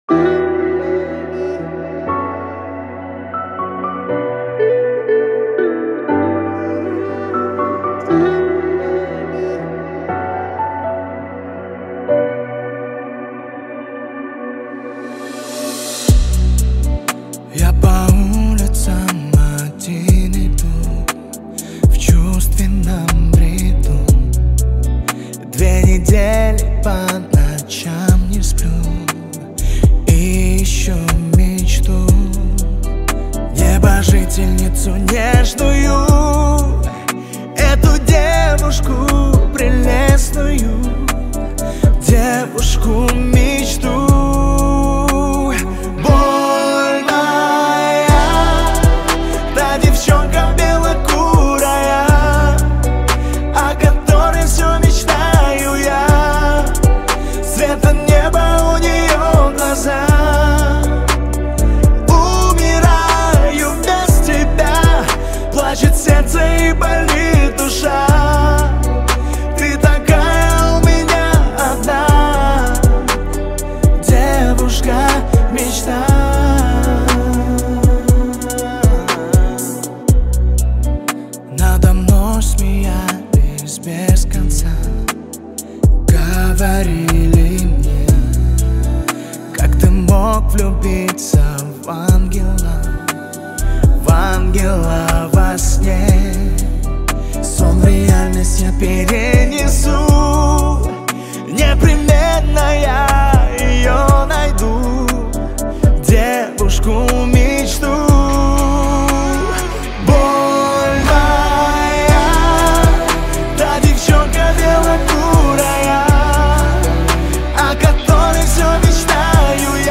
• Категория: Киргизские песни